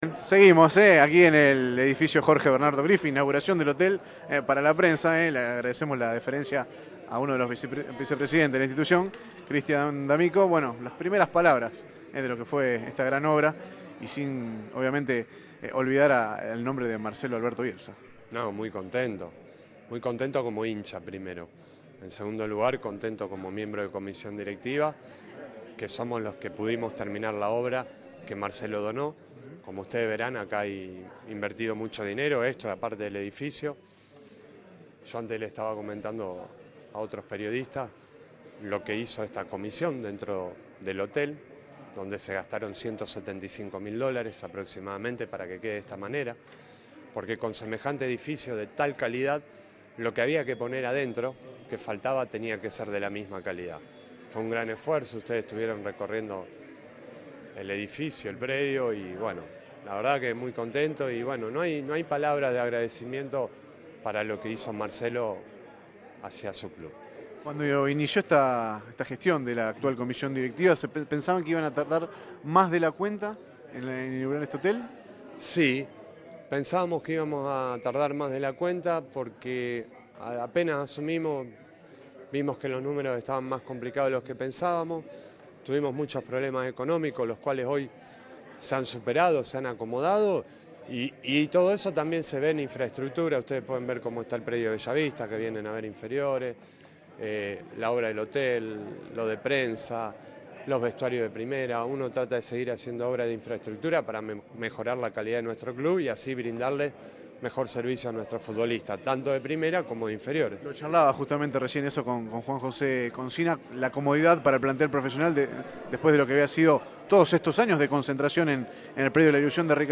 En el marco de la presentación para la prensa del Edificio Jorge Griffa